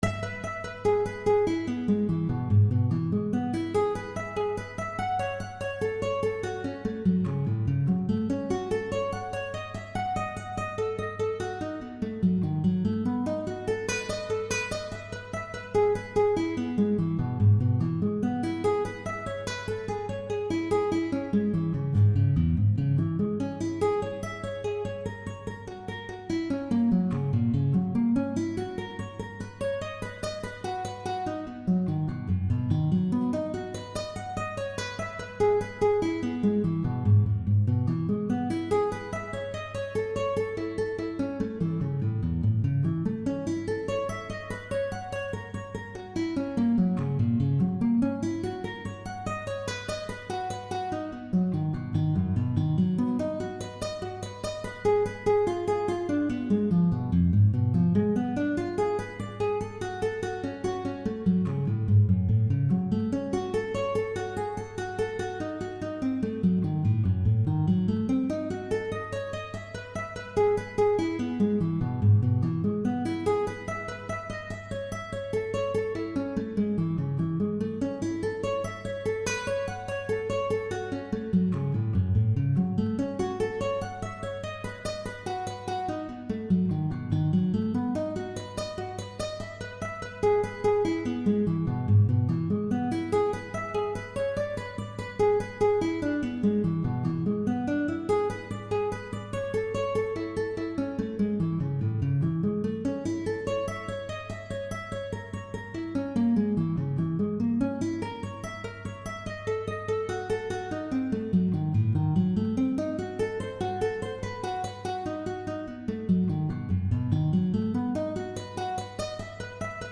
Inventions-senonaises-etude-pour-guitare-seule.wav